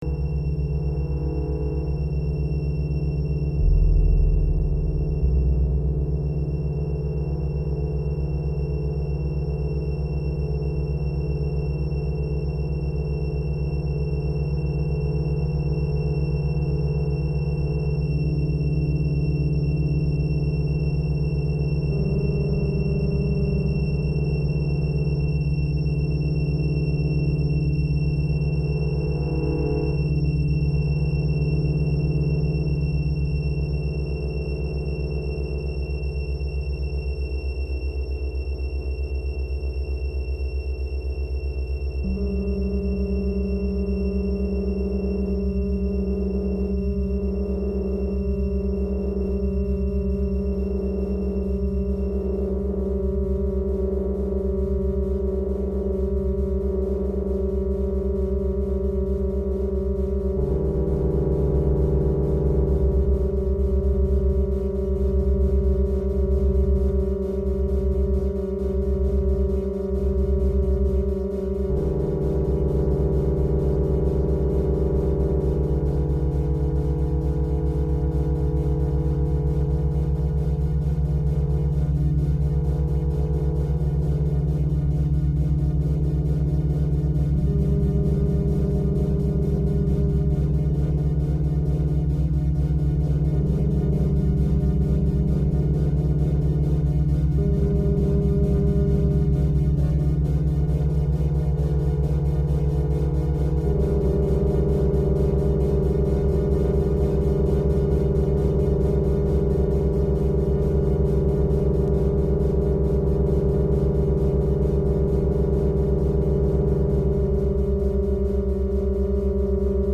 AMNISTIE PHR�NIQUE POUR CLAVECIN * * pour guitare et clavier �lectriques r�alis� sur Revox A700 et deux A77 deux pistes retour